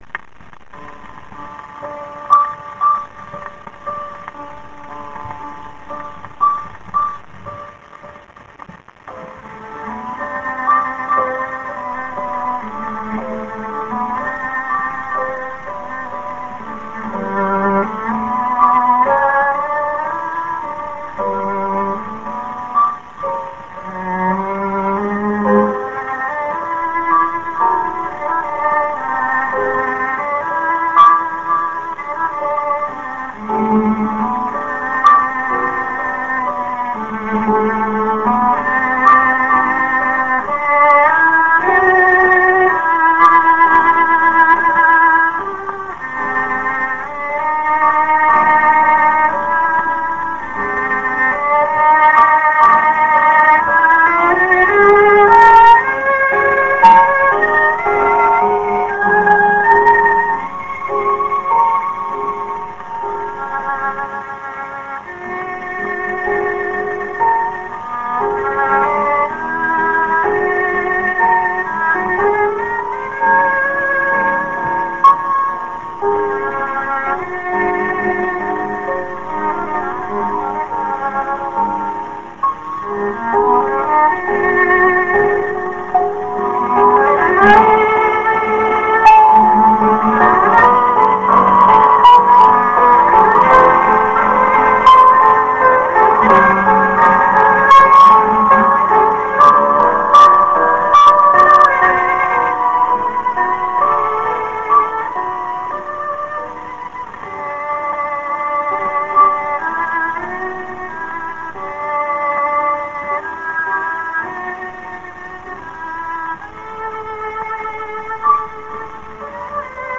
蓄音機から流れる音を録音してみました!!
ノスタルジックな雑音混じりの音楽を
ピアノ*ソロ
12インチSP盤